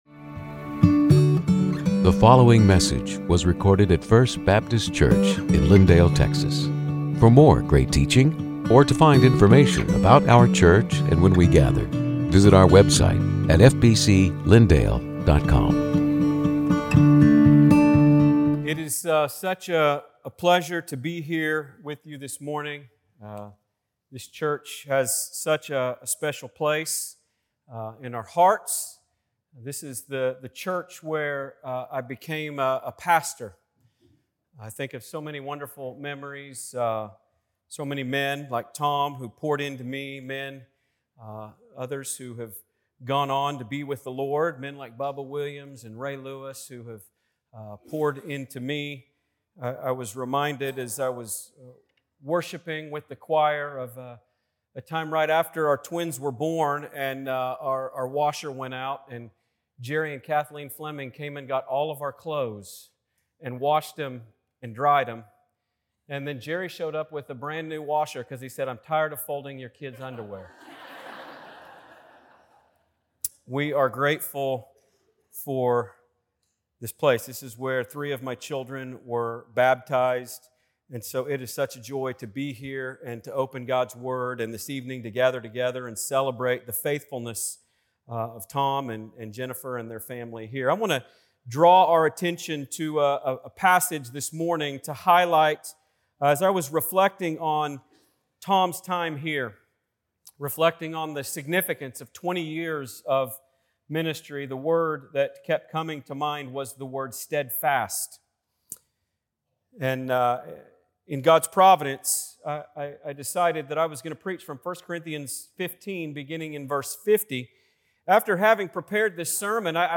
Sermons › 1 Corinthians 15:50-58